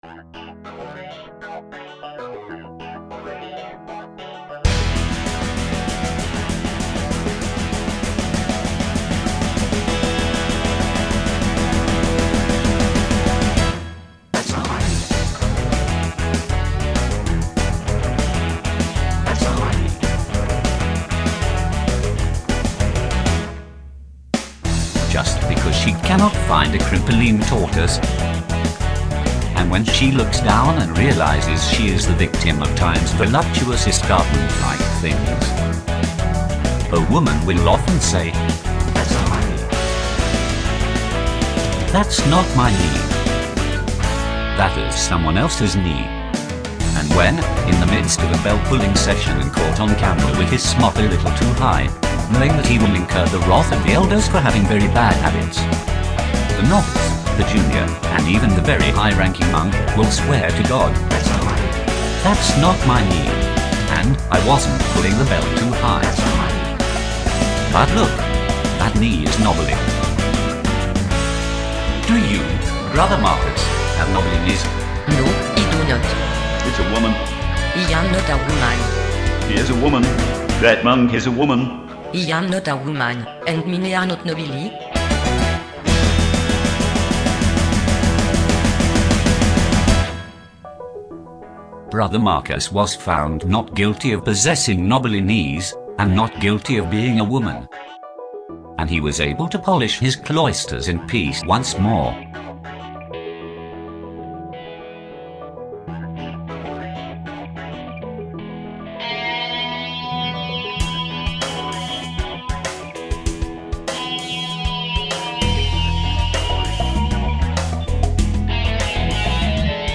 oompah and belly dance
Slide Guitars
Rhodes Piano